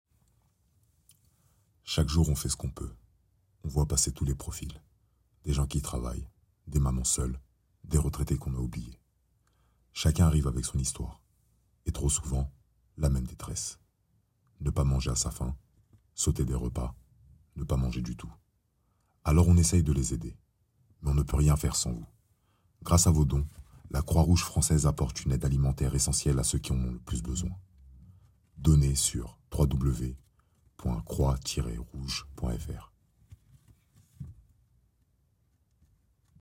30 - 55 ans - Basse Baryton-basse